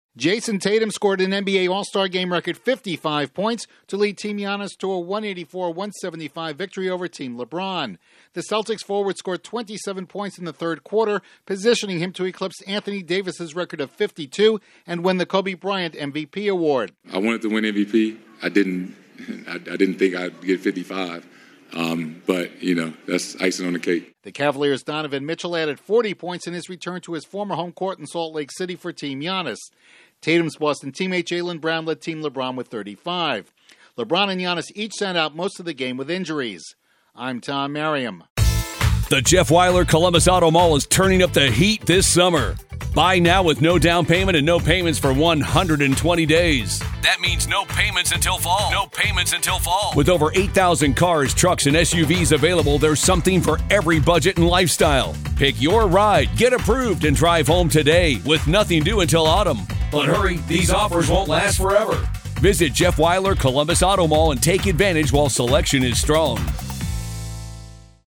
The top player from the top team takes top honors in the NBA All-Star Game. Correspondent